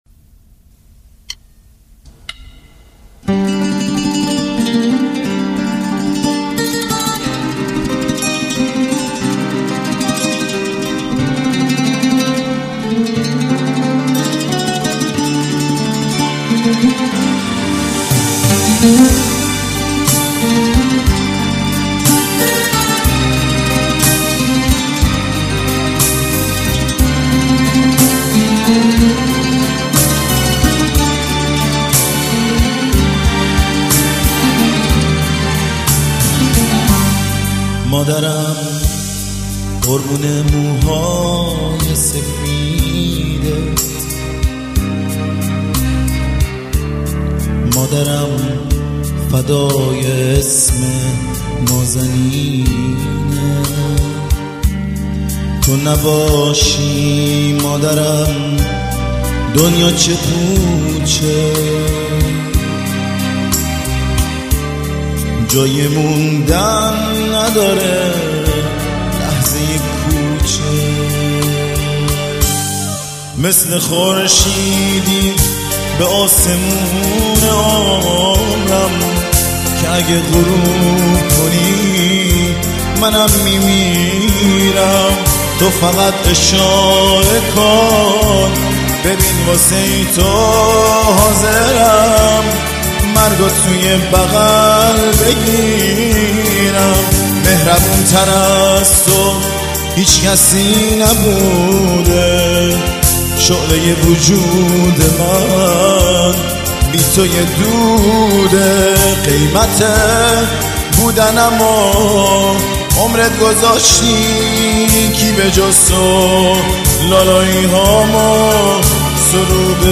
• دسته بندی ایرانی پاپ